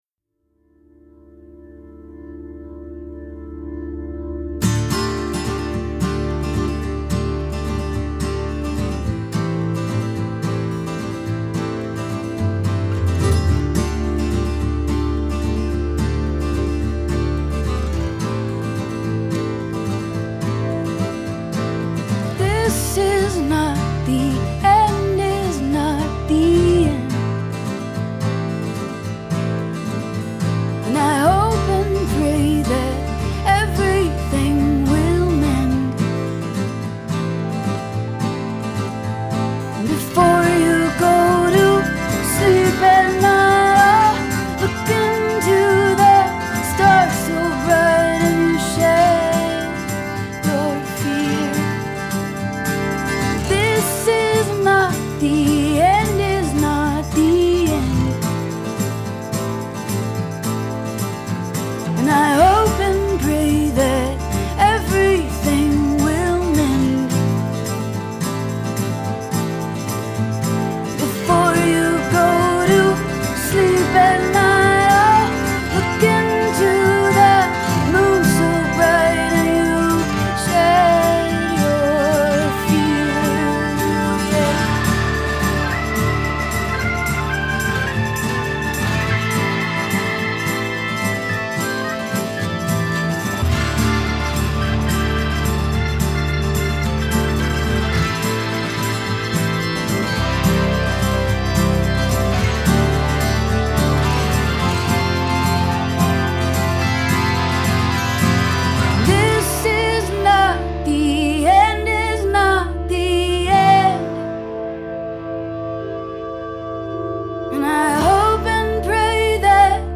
strong, nuanced singer/songwriter fare